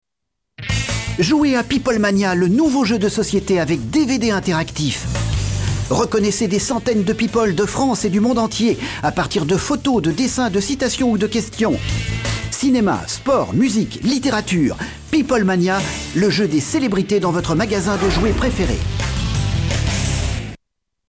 Sprecher französisch.
Kein Dialekt
Sprechprobe: eLearning (Muttersprache):
french voice over artist